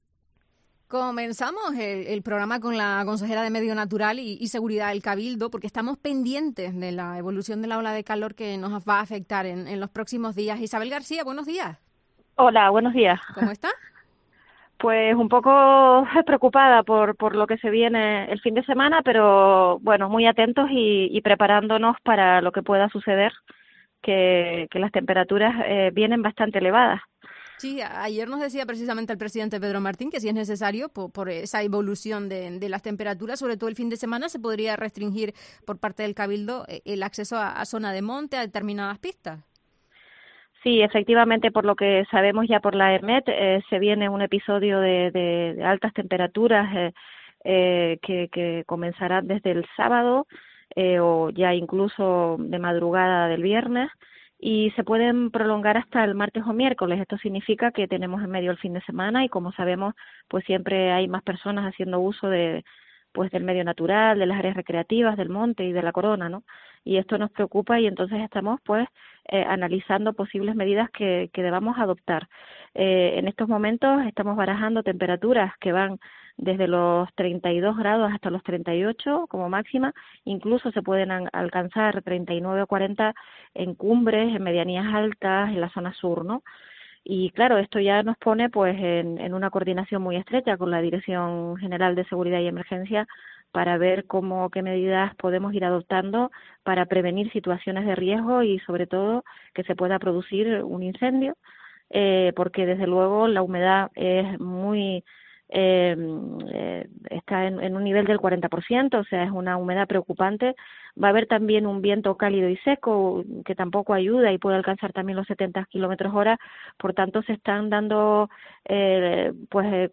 Entrevista a Isabel García, consejera de Medio Natural del Cabildo de Tenerife